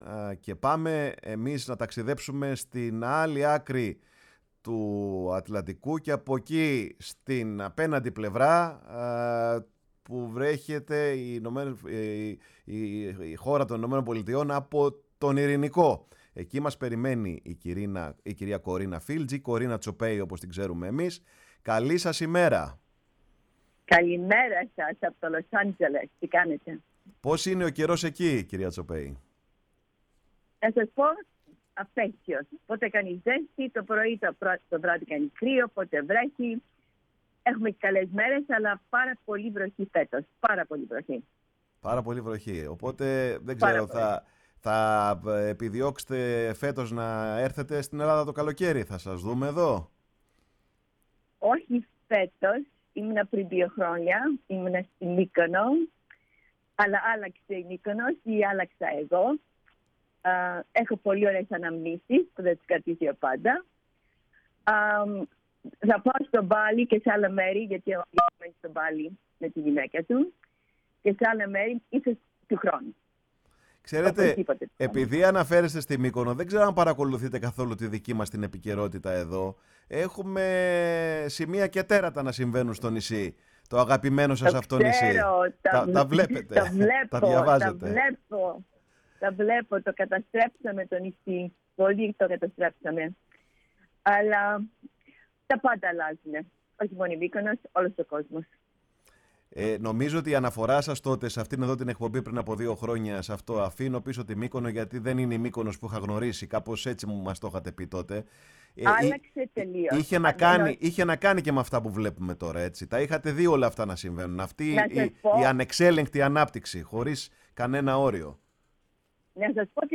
Η Κορίνα Τσοπέη μιλά και πάλι στο ραδιόφωνο της Φωνής της Ελλάδας, μετά από την πρώτη συνέντευξη που είχε δώσει στο τέλος του καλοκαιριού του 2021.